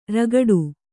♪ ragaḍu